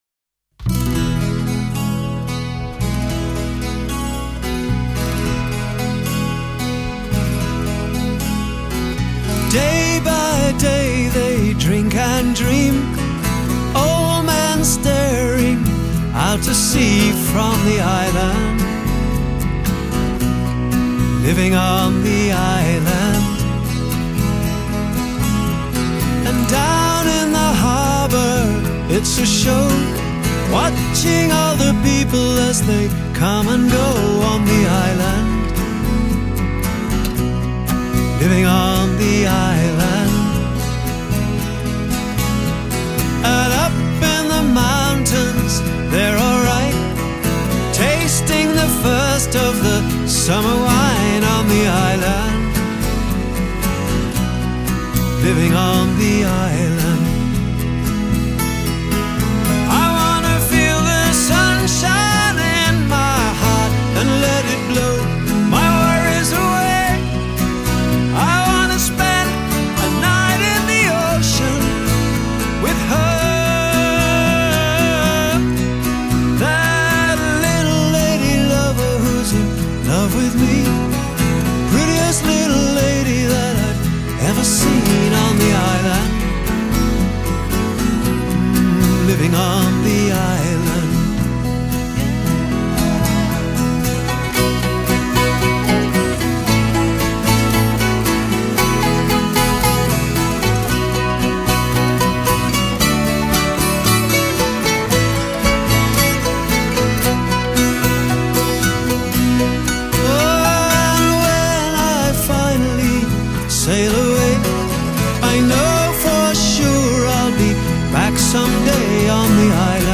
Genre: Pop, Soft Rock